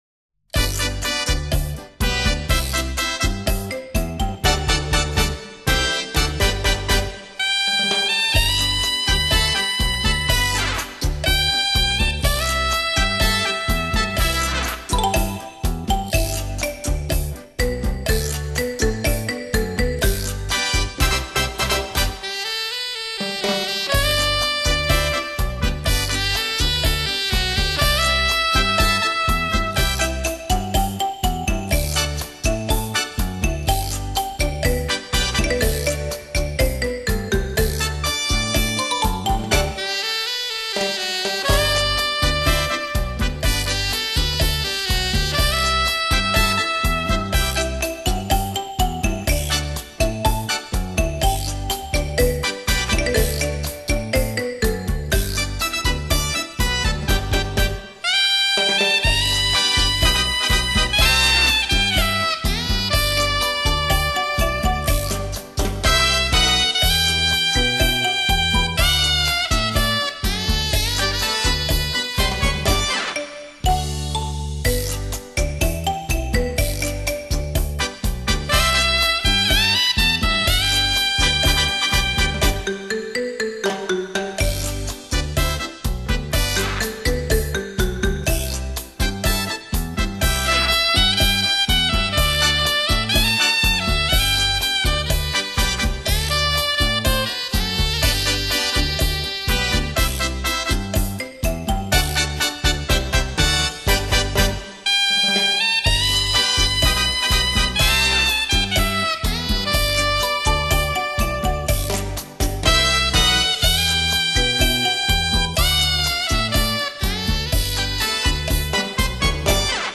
都是八十年代初的，配器、效果还算不错，特此上传与大家一起分享。
您现在试听的曲目是：第一段联奏
（试听为低品质wma，下载为320k/mp3）